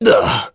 Lusikiel-Vox_Damage_01.wav